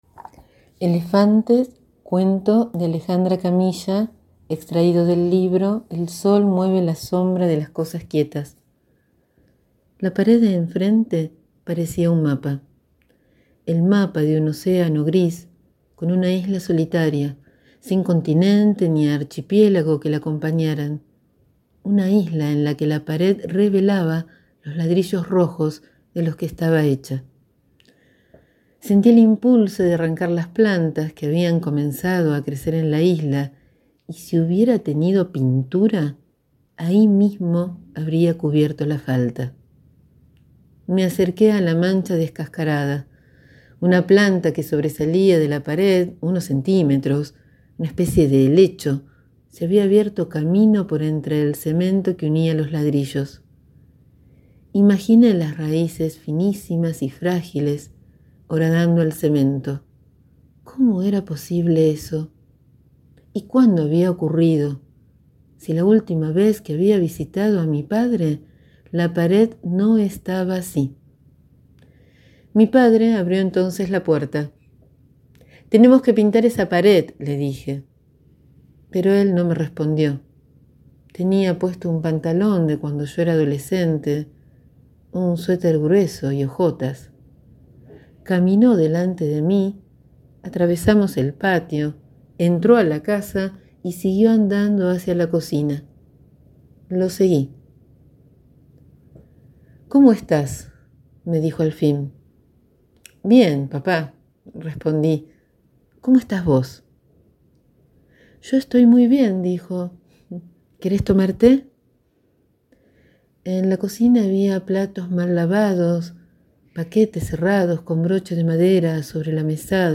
Un amigo de la casa nos surgirió la lectura de este cuento y para nosotrxs fue una alegría recibir la sugerencia y también grabarlo para que quede en este sitio.